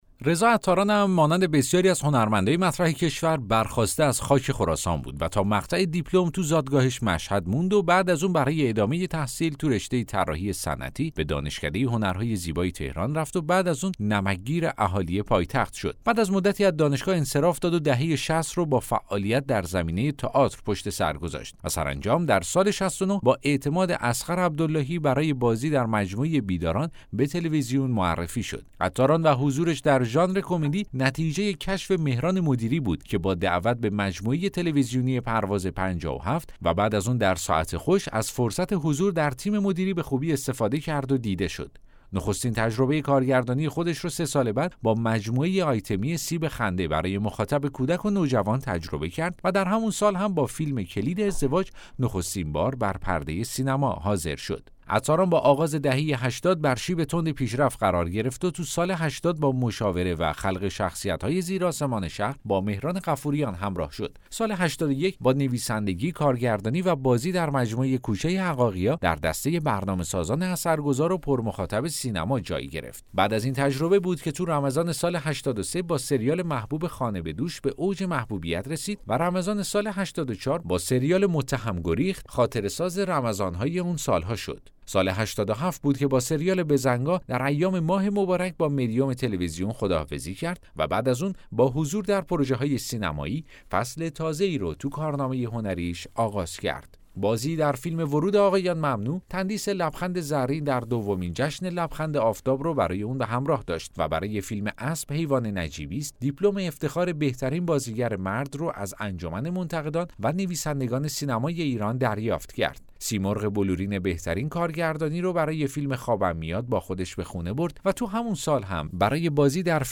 داستان صوتی: تولد یک ستاره